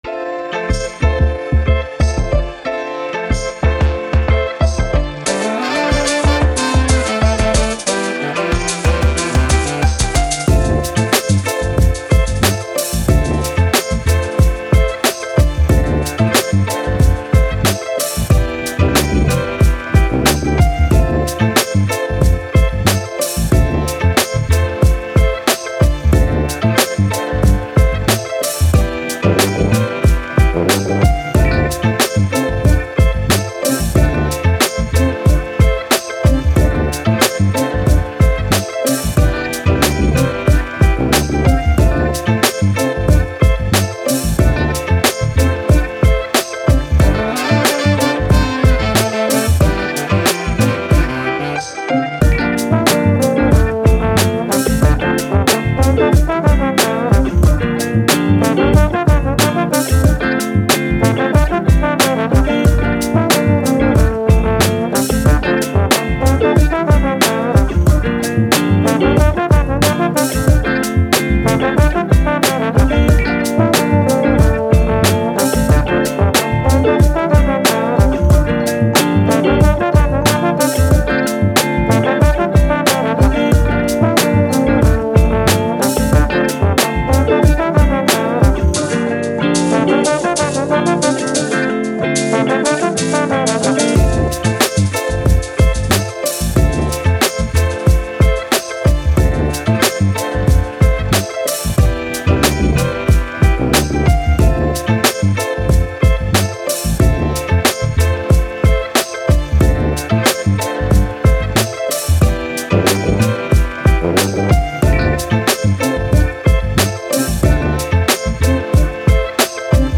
Hip Hop, Action, Upbeat, Funky